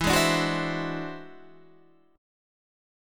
E Augmented 9th